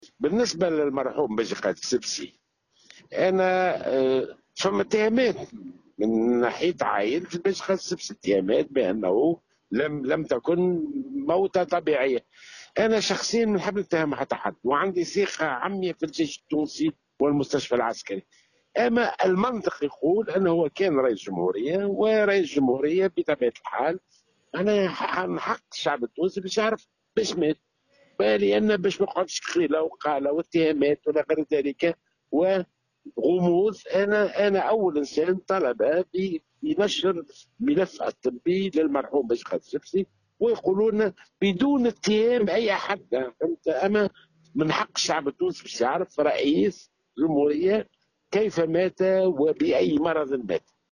S’exprimant au micro de Tunisie Numérique, le président de l’Alliance Nationale Tunisienne Neji Jalloul a commenté l’ouverture d’une enquête sur la mort “suspecte” de l’ancien président de la République Béji  Caied Essebssi sur ordre de la ministre de la Justice Leila Jaffel.